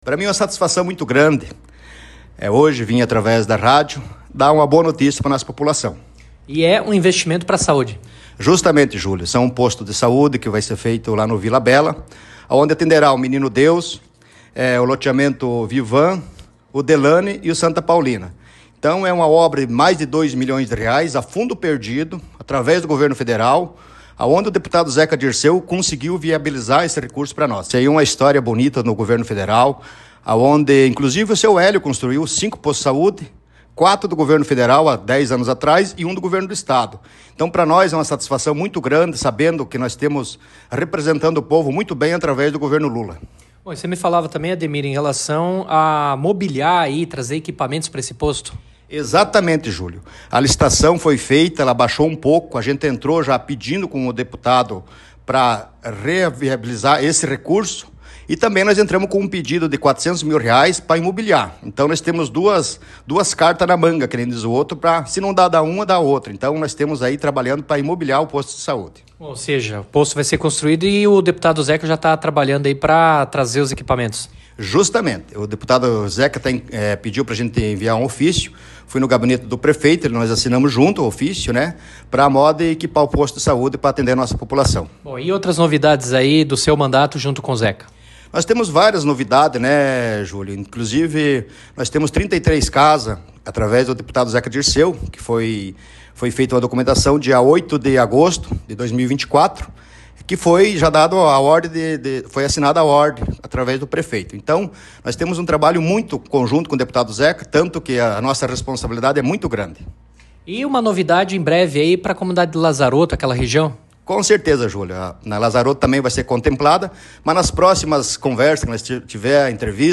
Ademir de Oliveira concedeu entrevista ao Jornalismo Ampére AM/Interativa FM: